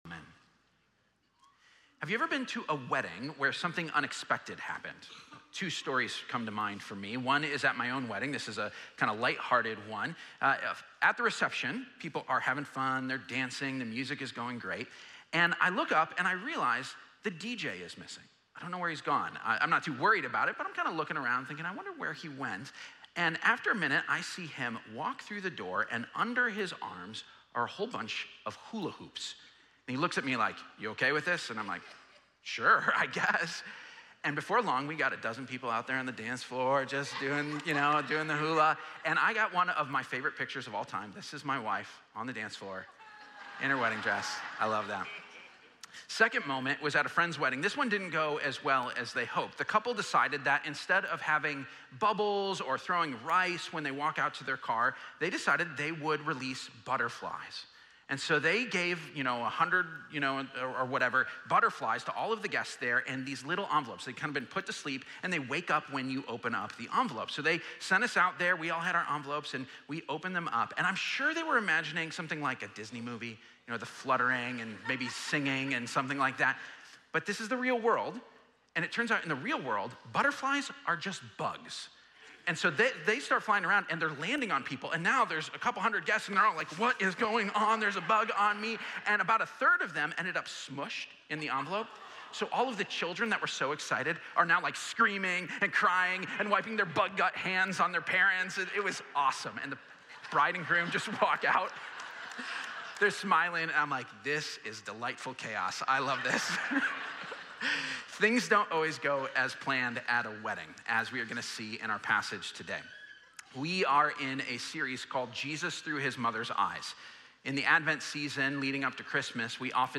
12-8-24-Sermon.mp3